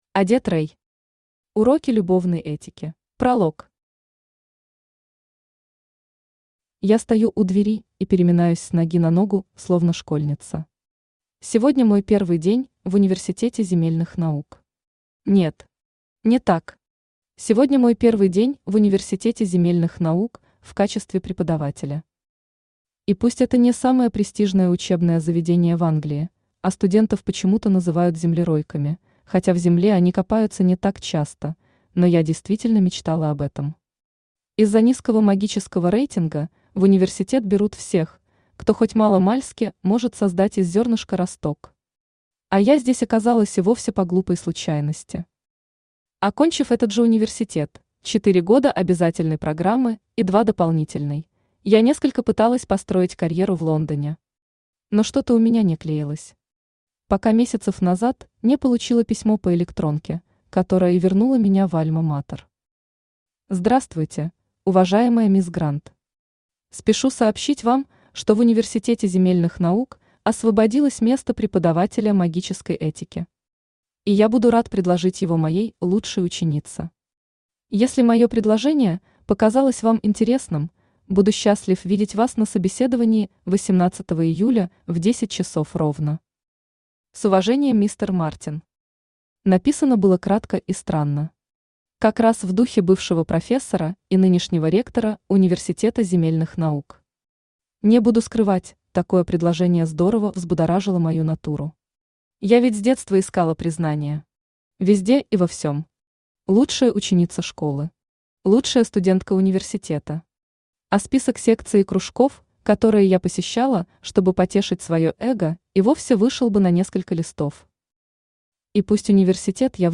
Аудиокнига Уроки любовной этики | Библиотека аудиокниг
Aудиокнига Уроки любовной этики Автор Одетт Рей Читает аудиокнигу Авточтец ЛитРес.